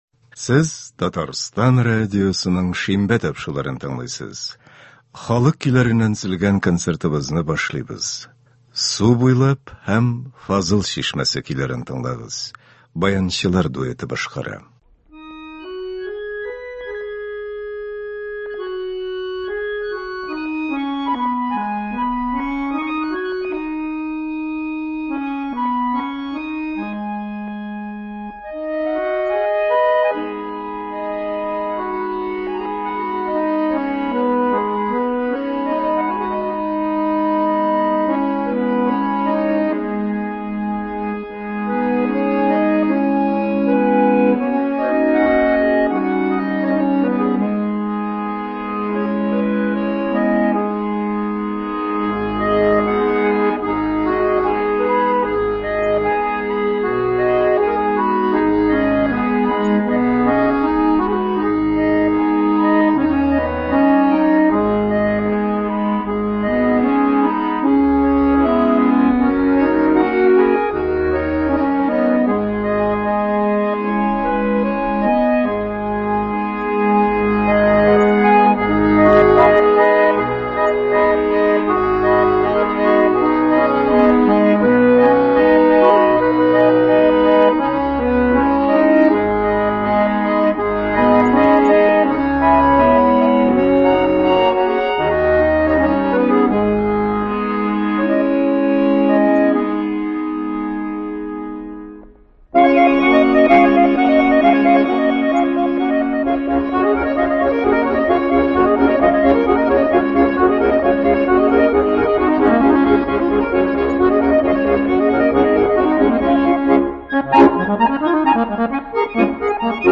Татар халык көйләре (19.11.22)
Бүген без сезнең игътибарга радио фондында сакланган җырлардан төзелгән концерт тыңларга тәкъдим итәбез.